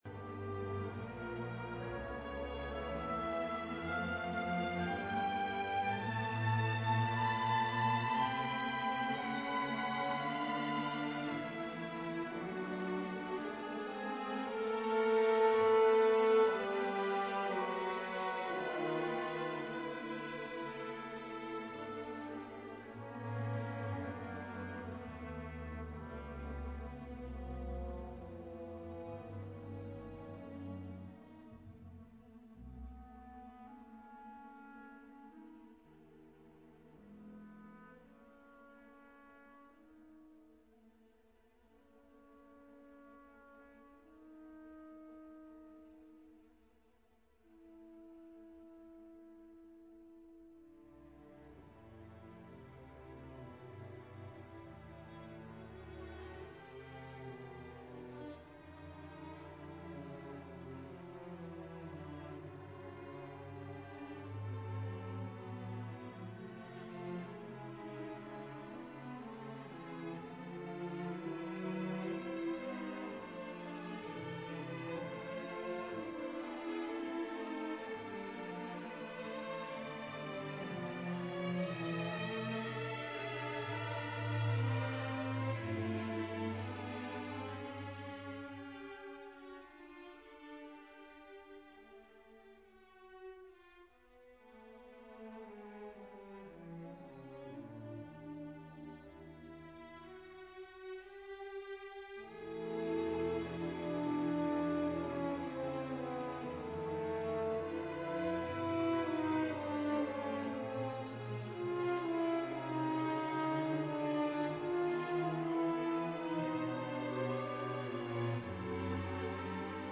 Adagio-Allegro (excerpts)